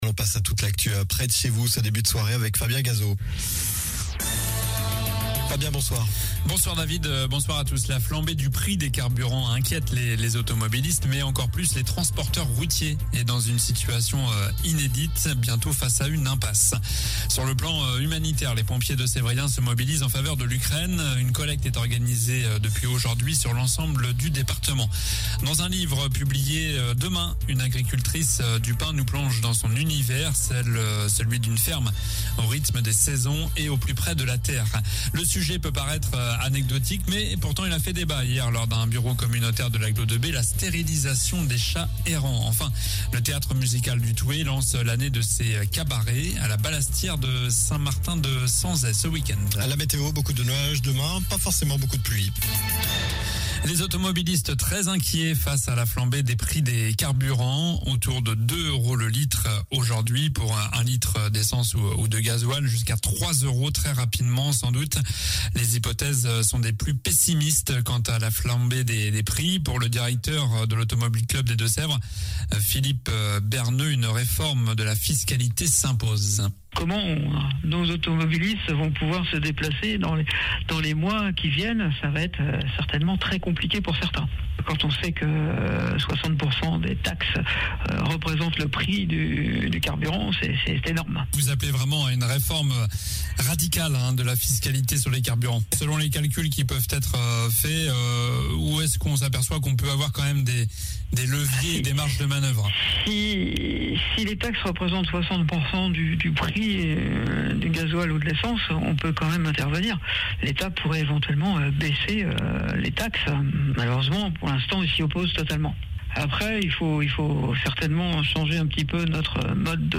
Journal du mercredi 09 mars (soir)